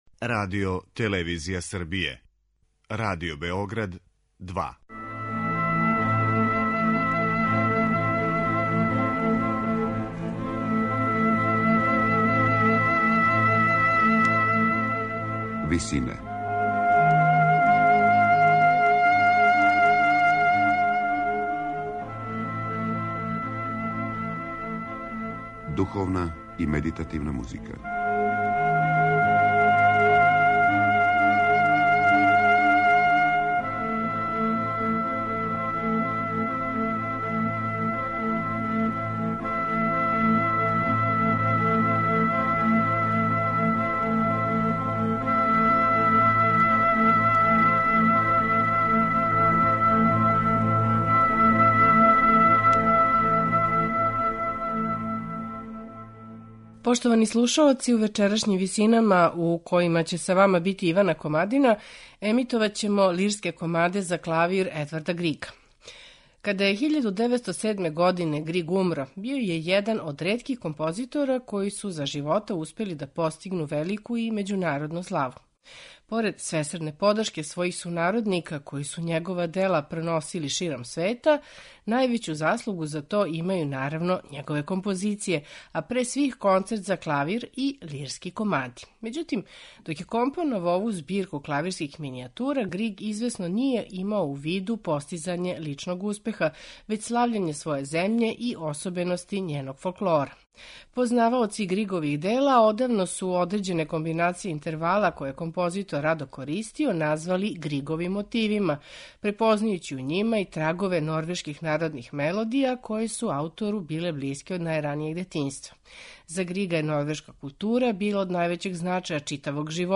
Међутим, док је током готово четири деценије компоновао ову збирку клавирских минијатура, Григ, извесно, није имао у виду постизање личног успеха, већ слављење своје земље и особености њеног фолклора.
У вечерашњим Висинама Григове „Лирске комаде" слушаћемо у интерпретацији пијанисте Хавијера Перијанеса.